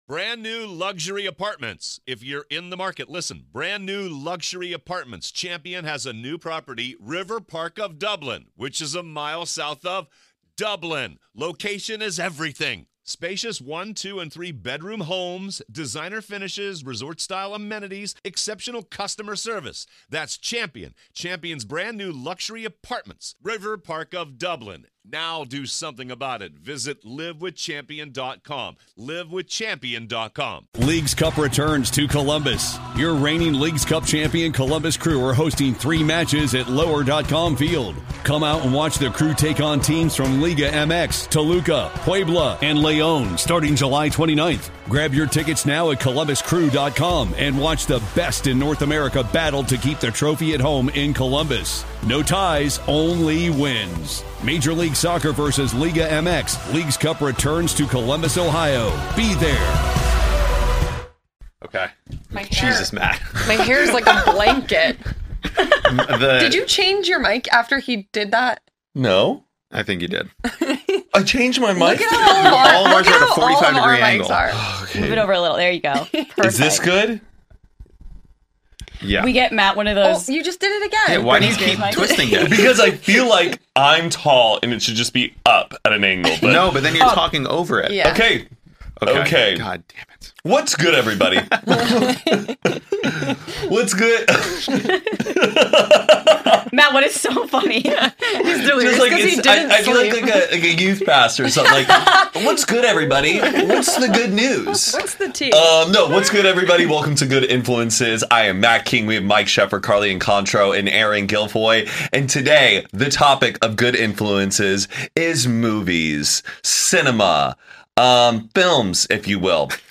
The 4 hosts of Hoot & a Half and Only Friends have come together to create a supergroup show, where each week we will dive into and discuss, answer your questions, and more.